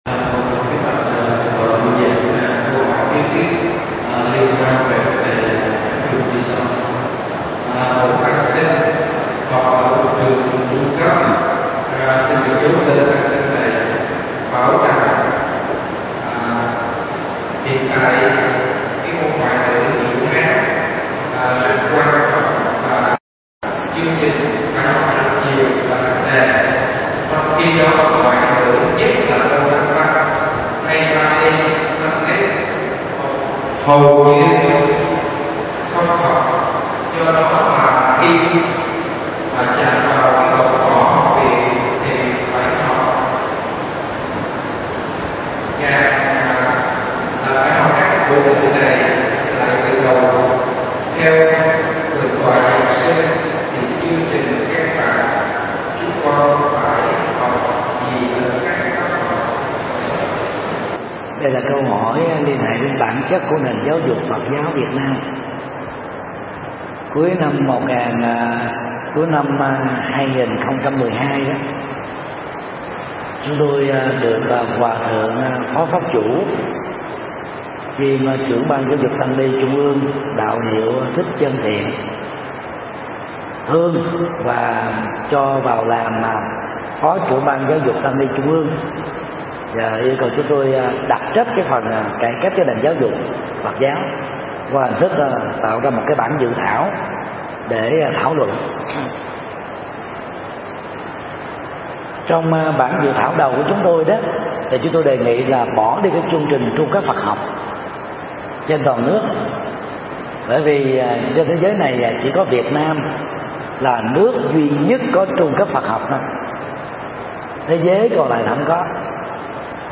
Vấn đáp: Giáo dục Phật giáo – thầy Thích Nhật Từ mp3